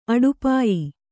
♪ aḍupāyi